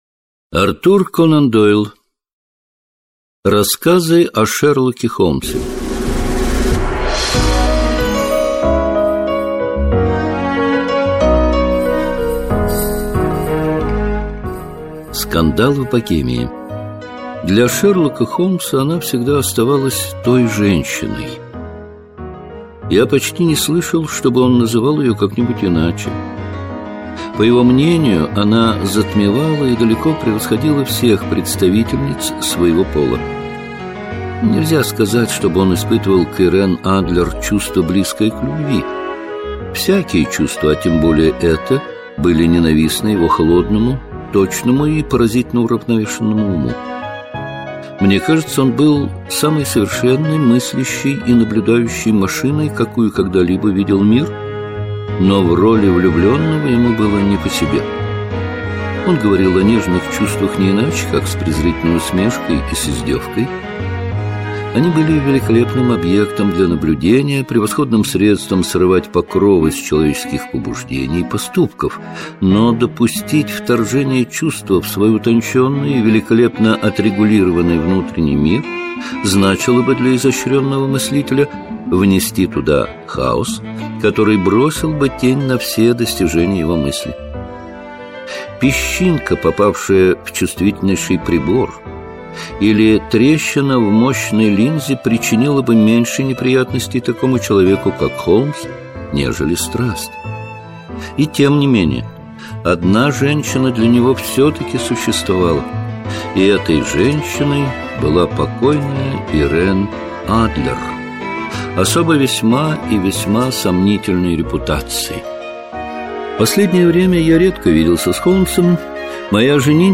Аудиокнига Рассказы о Шерлоке Холмсе | Библиотека аудиокниг